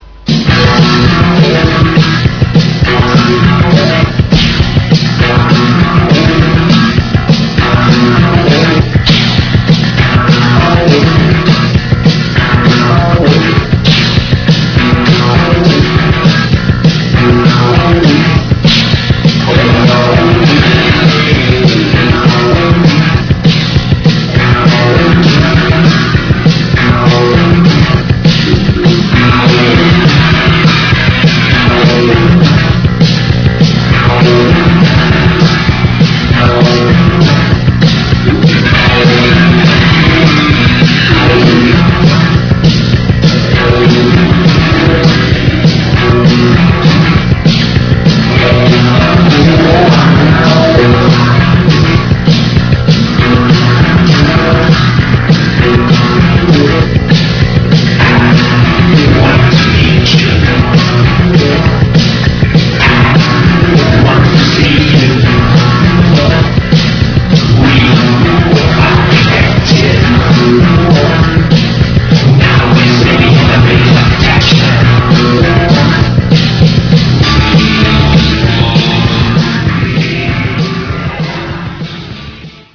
CYBERPUNK